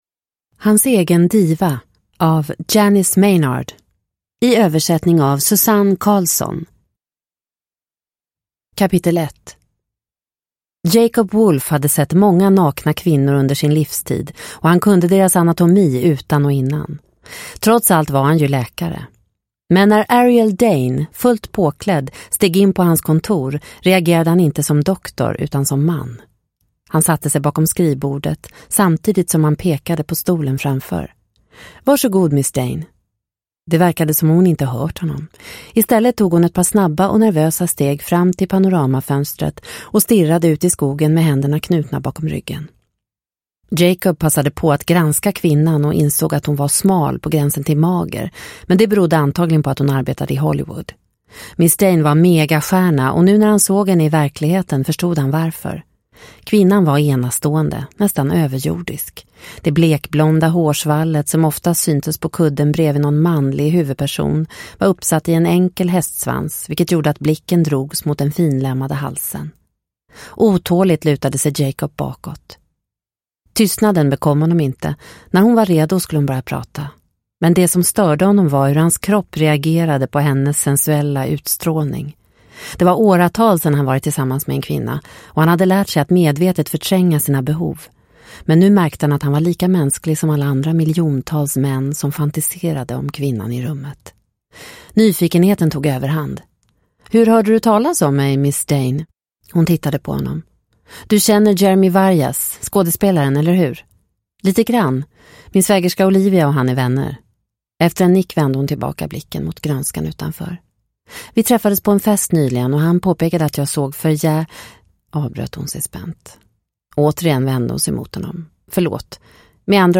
Hans egen diva – Ljudbok – Laddas ner